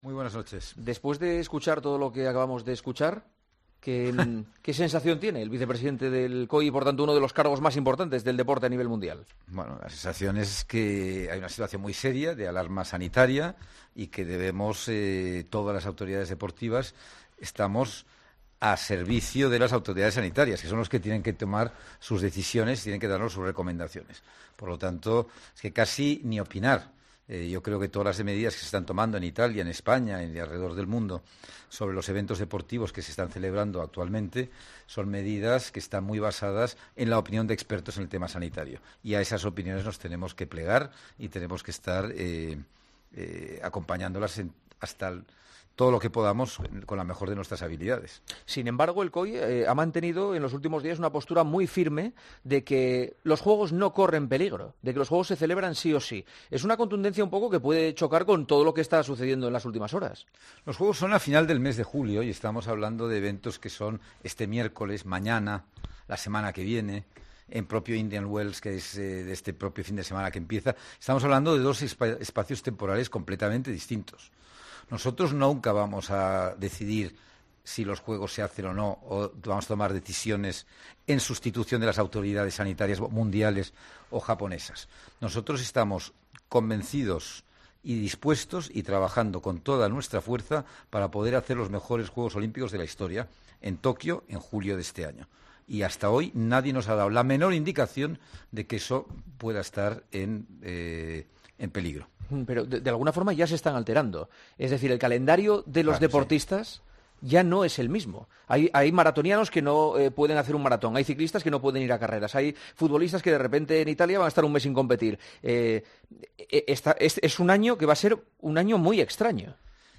AUDIO DE LA ENTREVISTA A SAMARANCH